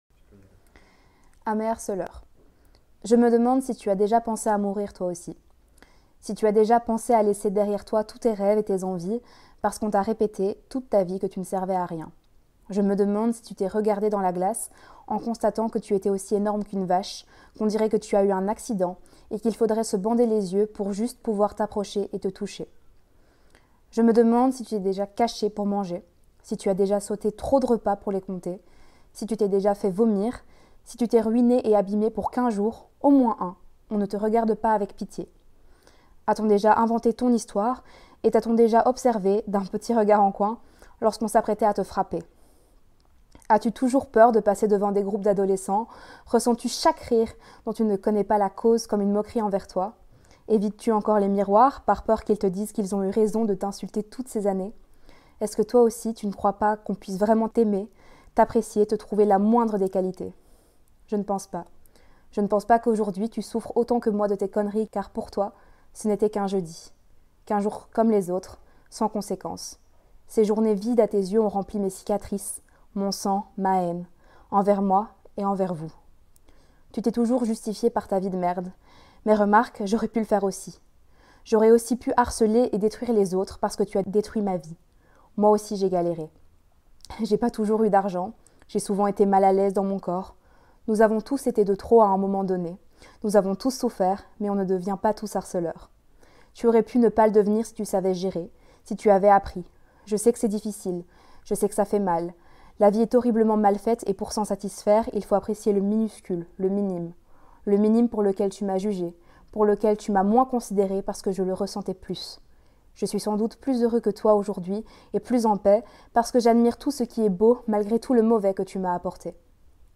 Vous voyagerez entre histoires réelles et interviews avec des spécialistes.